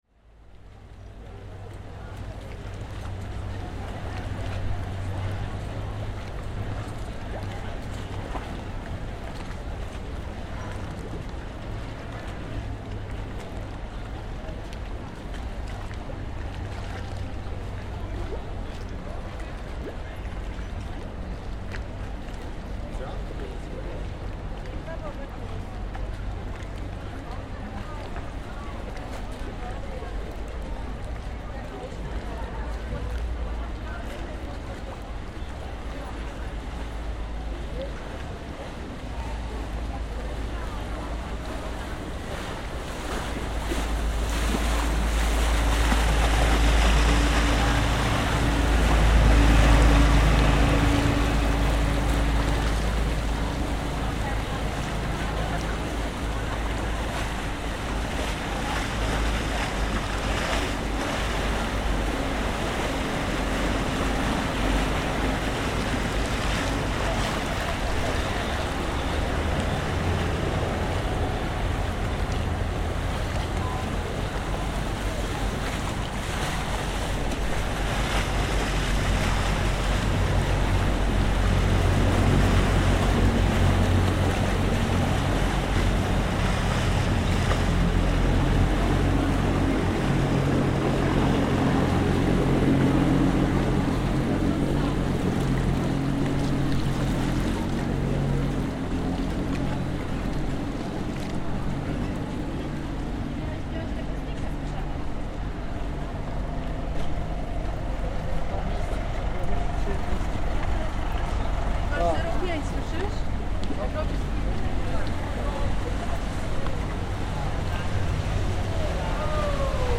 On top of Ponte Tre Ponti in Venice, we listen to the classic soundscape of the city, in which passing boats replace the low thrum of cars, vans, motorbikes and lorries. This is a busy section of canal which is what passes for heavy traffic in this most unique of cities.